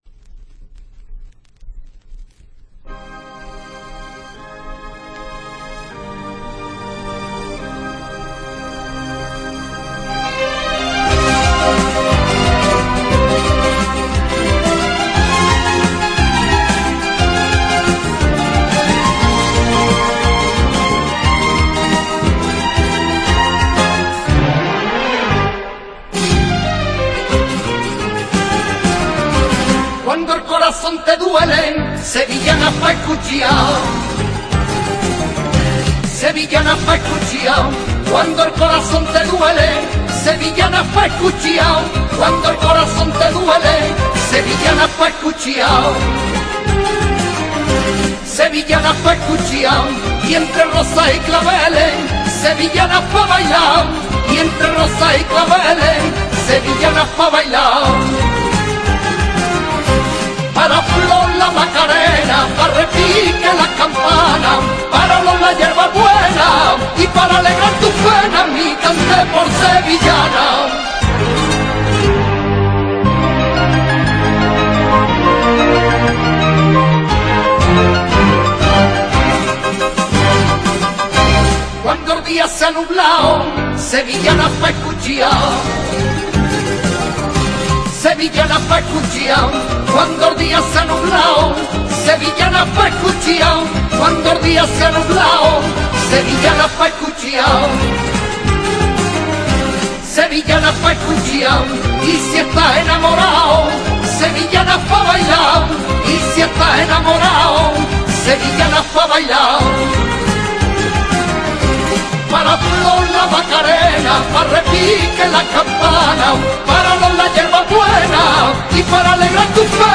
una entrega semanal de sevillanas para la historia.